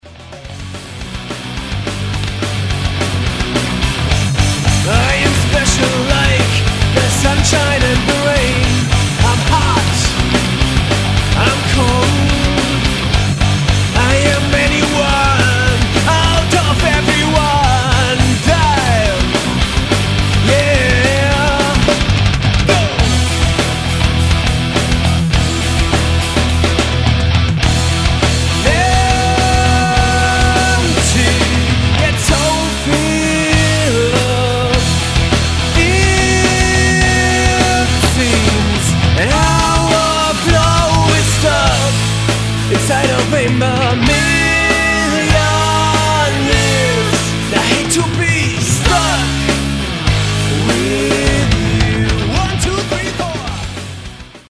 Rockt. Oder punked?